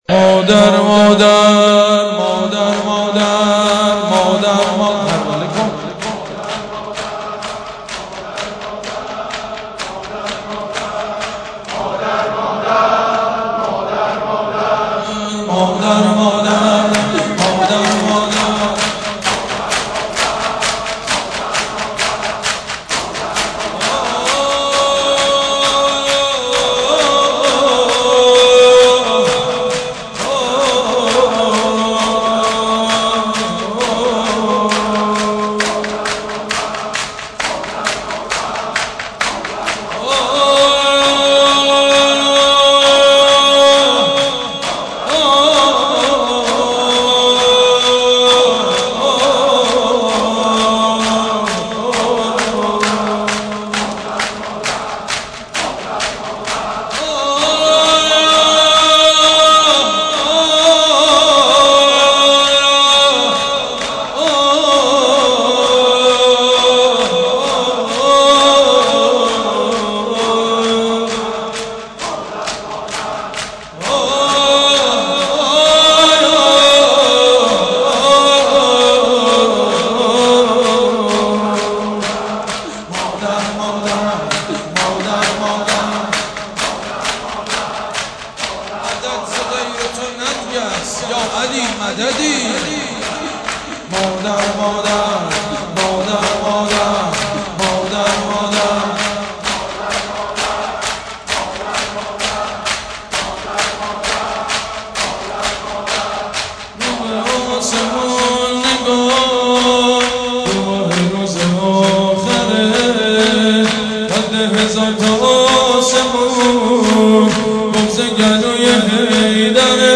سینه زنی در شهادت بانوی دوعالم حضرت زهرا(س